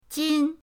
jin1.mp3